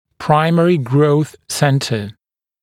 [‘praɪmərɪ grəuθ ‘sentə][‘праймэри гроус ‘сэнтэ]первичный центр роста